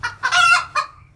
Chicken Single Alarm Call
Category 🐾 Animals
animal bird cackle call chick chicken Chicken chickens sound effect free sound royalty free Animals